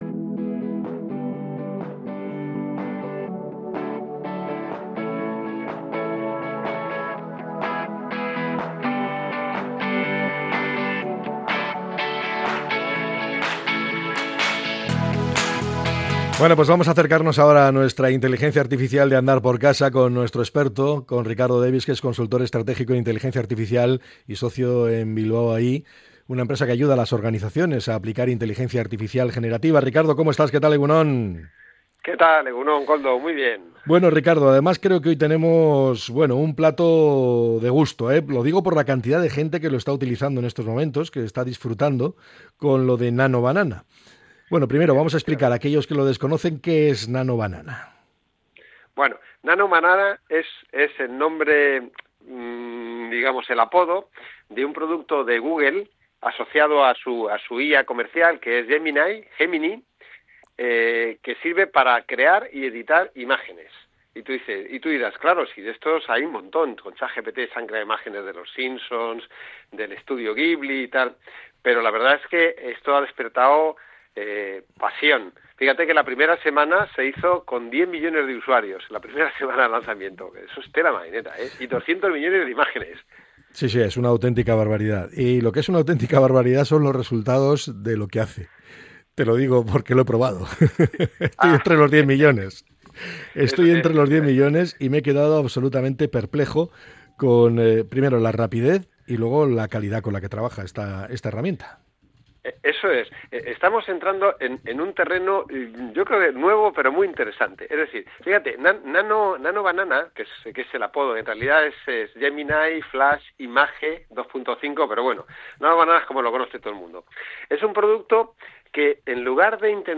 En la sección IA para andar por casa del programa EgunOn Bizkaia de Radio Popular – Herri Irratia